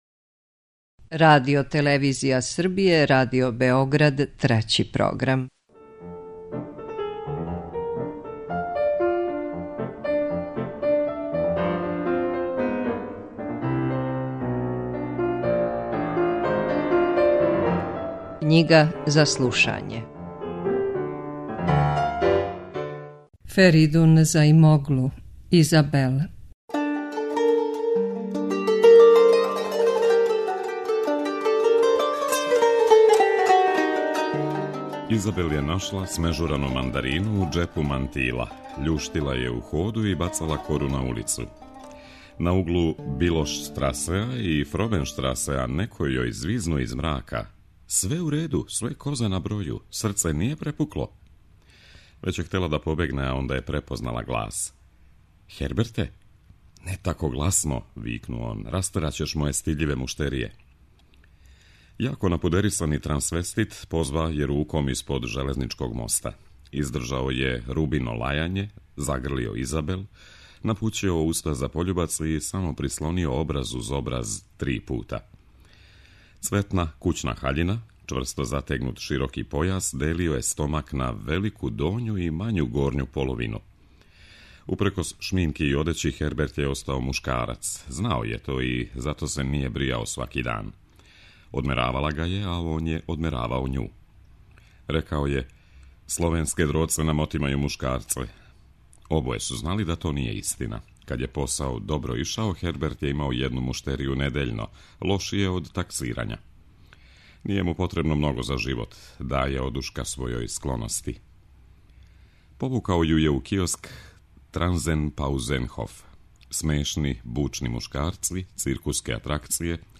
Књига за слушање